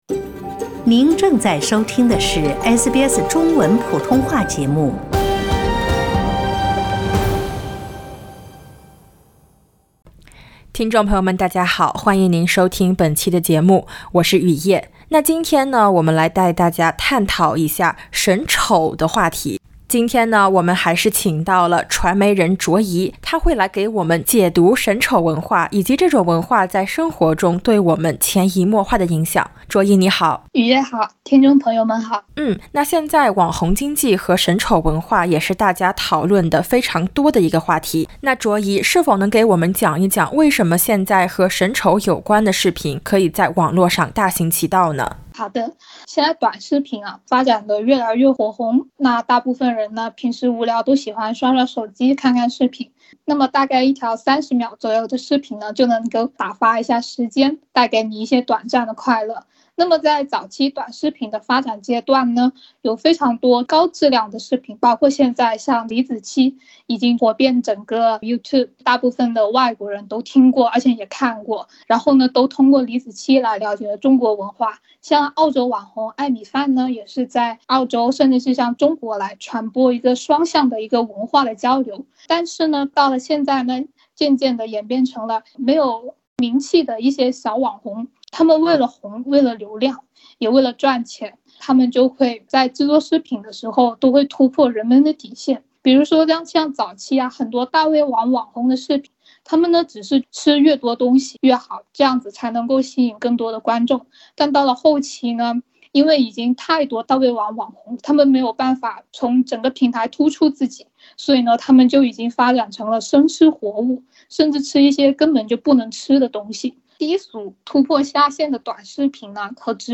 欢迎点击封面音频，收听完整采访。